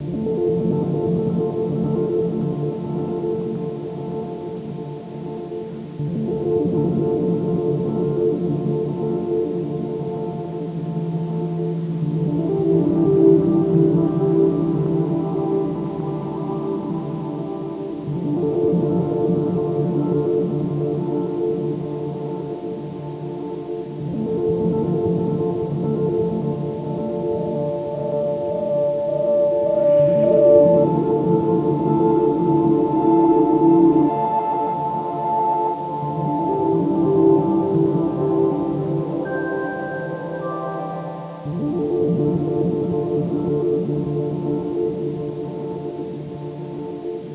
ambiance0.wav